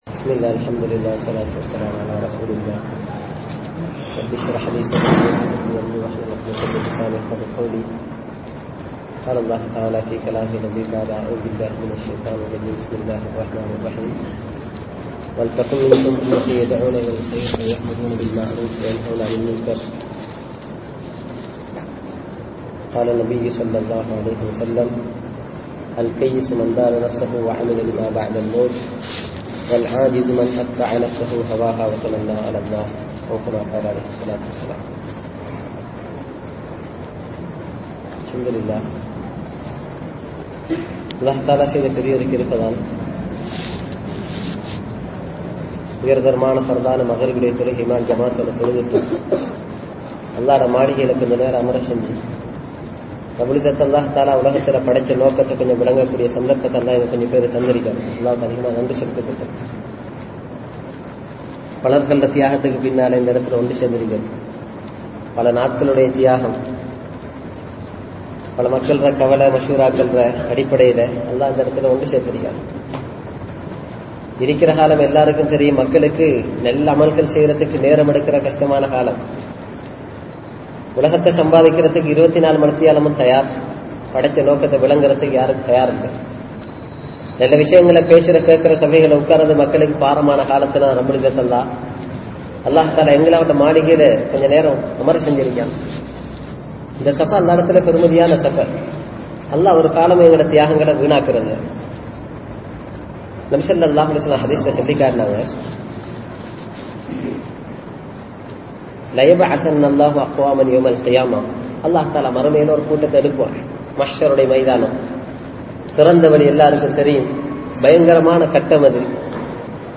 Maranaththitkaana Thayaarippu (மரணத்திற்கான தயாரிப்பு) | Audio Bayans | All Ceylon Muslim Youth Community | Addalaichenai